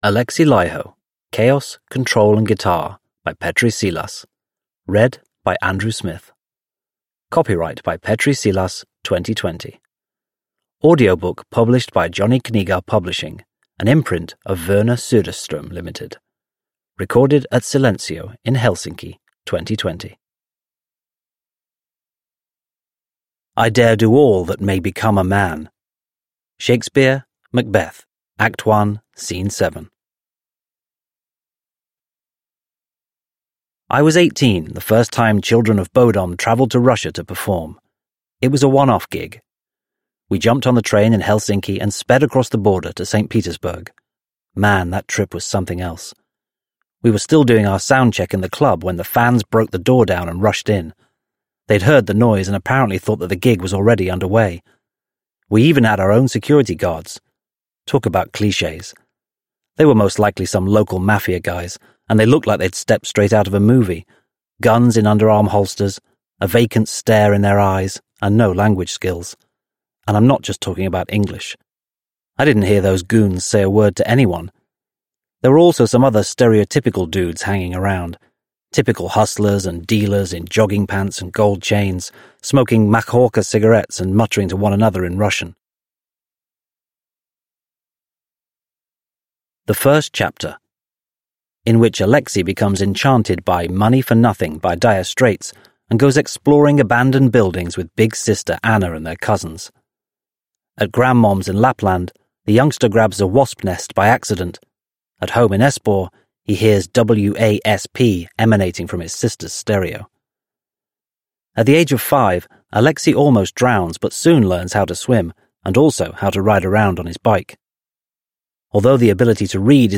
Alexi Laiho – Chaos, Control & Guitar – Ljudbok